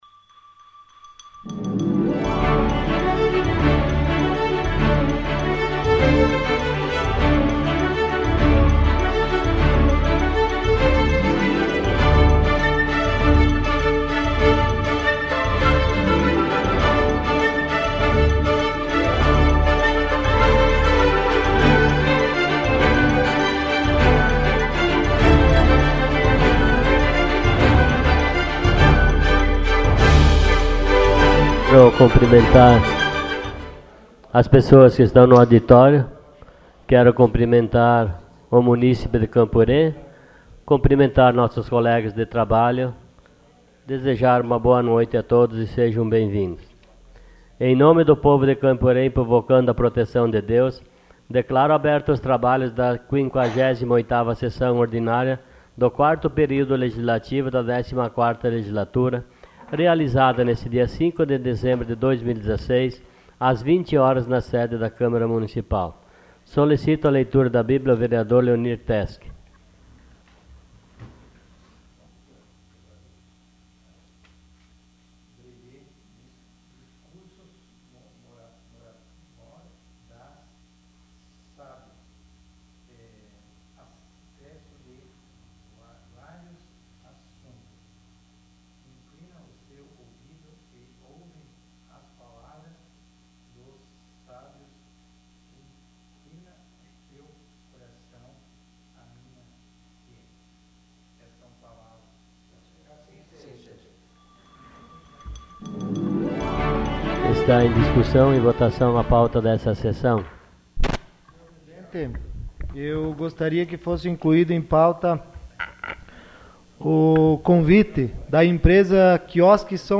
Sessão Ordinária dia 05 de dezembro de 2016.